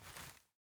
sr2m_holster.ogg